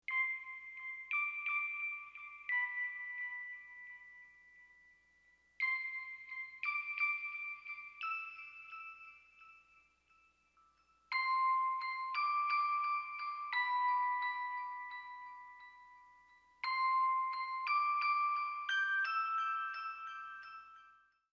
17. Electric Piano / Glockenspiel
Three glockenspiel and electric piano sounds were stacked for this layer.
20-electric-piano.mp3